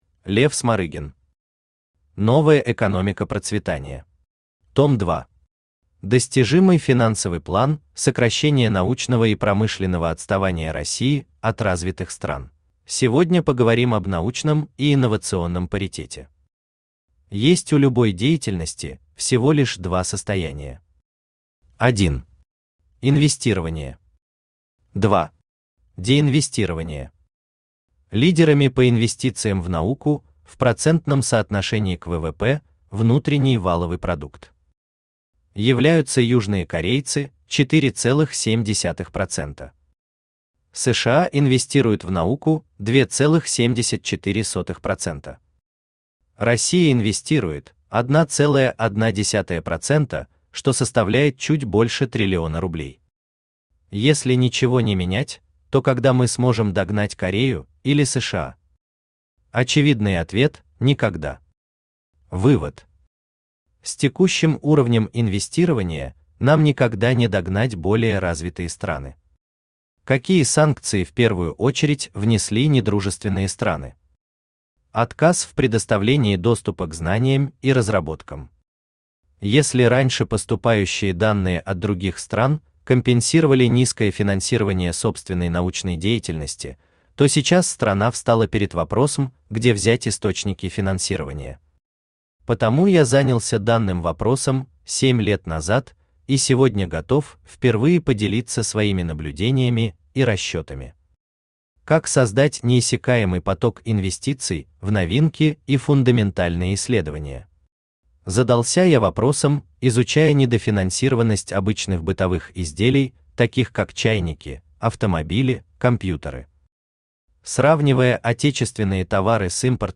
Том 2 Автор Лев Эдуардович Смарыгин Читает аудиокнигу Авточтец ЛитРес.